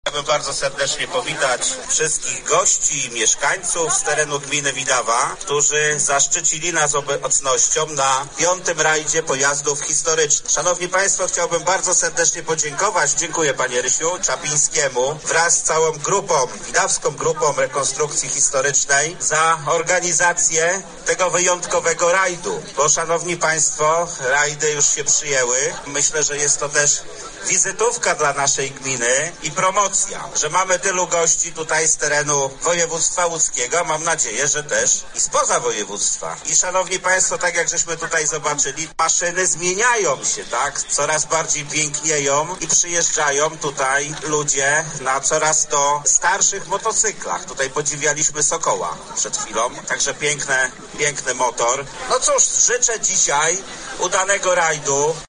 Uczestników wydarzenia powitał wójt, Michał Włodarczyk: